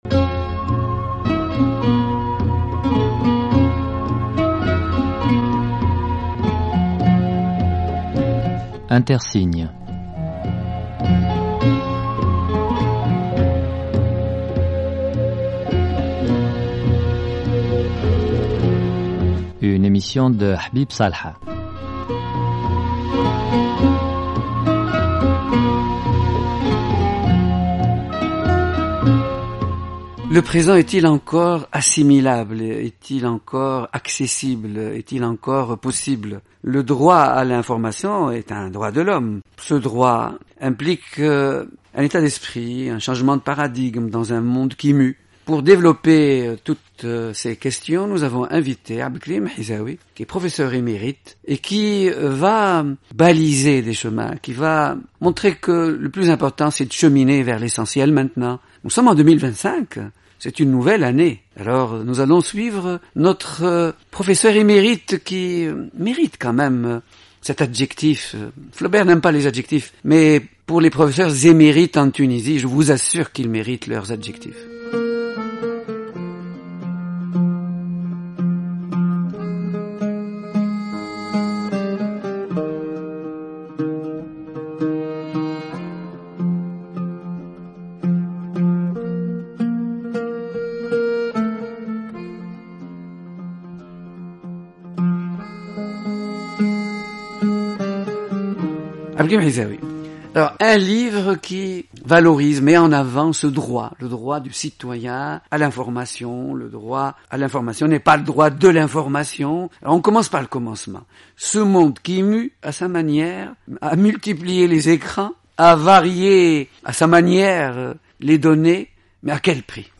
Découvrez l’intégralité de cet entretien dans l'extrait audio ci-dessous. https